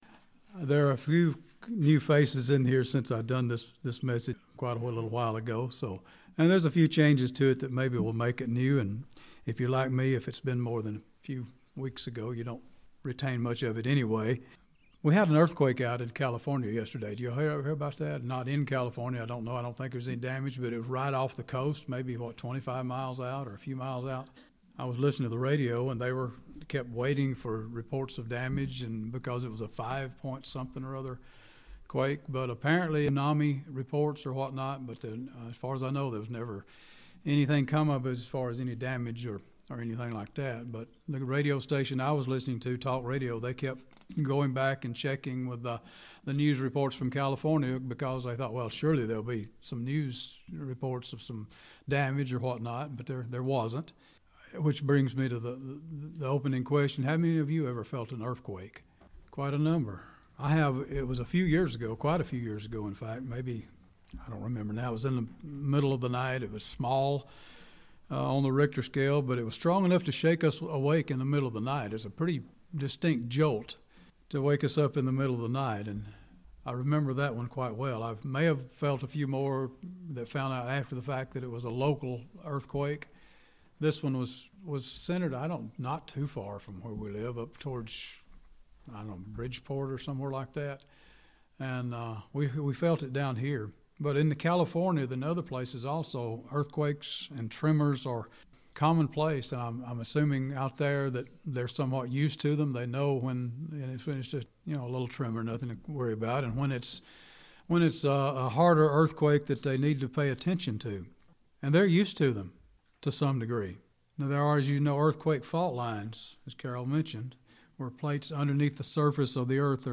Given in Gadsden, AL